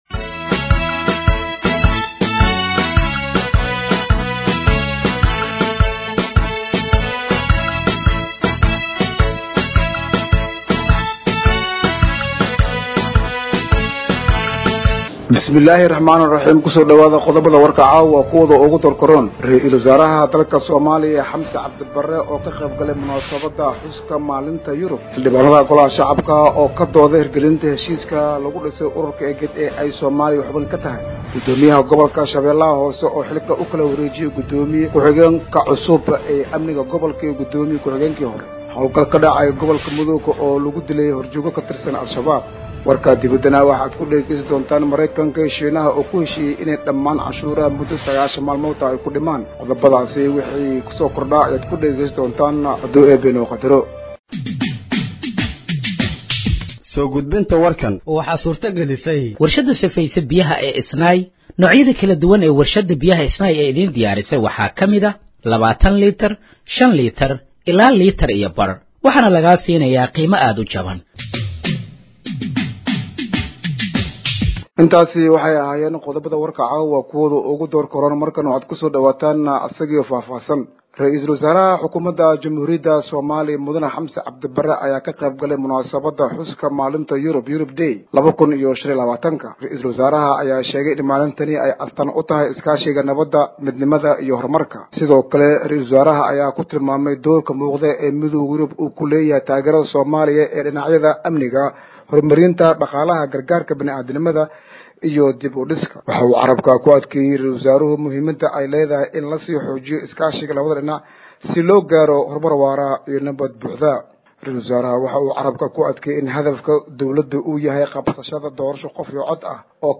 Dhageeyso Warka Habeenimo ee Radiojowhar 12/05/2025
Halkaan Hoose ka Dhageeyso Warka Habeenimo ee Radiojowhar